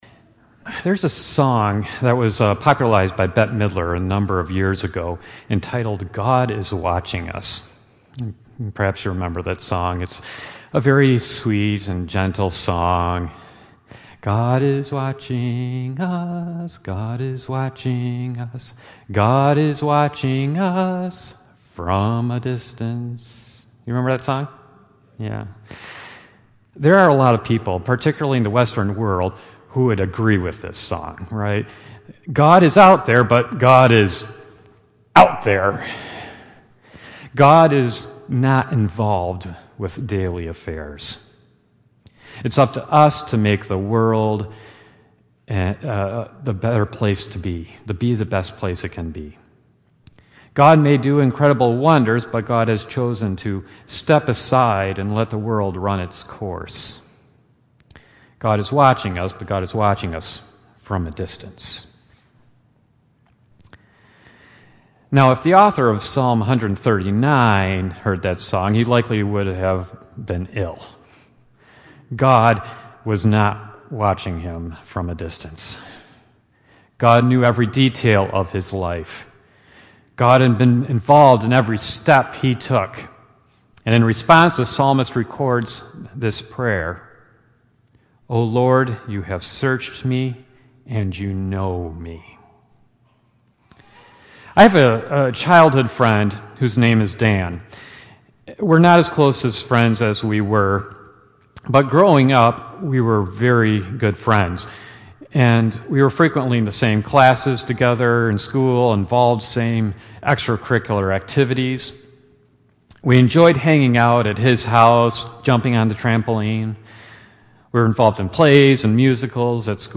Services from St. Andrew's | St. Andrew’s Presbyterian Church